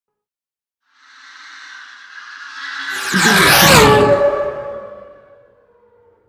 Sci fi vehicle pass by
Sound Effects
futuristic
pass by